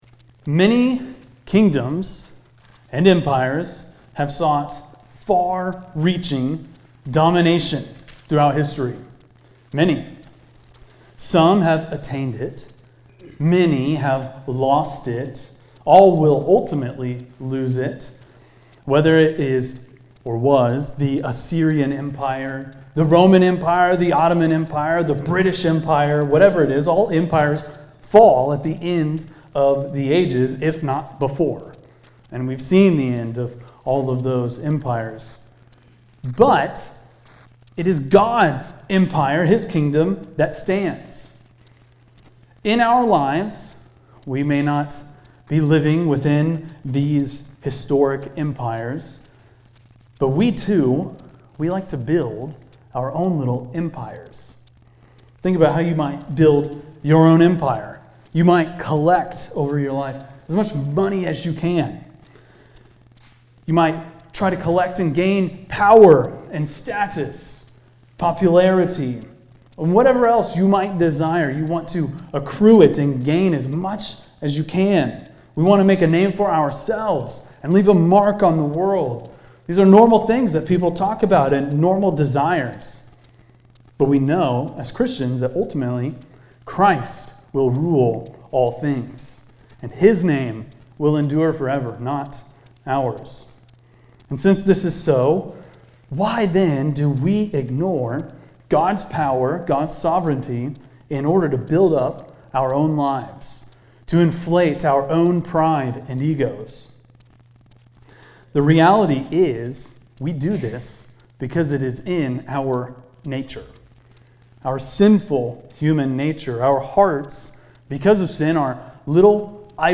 preaches through Proverbs 16:1-20 focusing on God's sovereignty and human pride.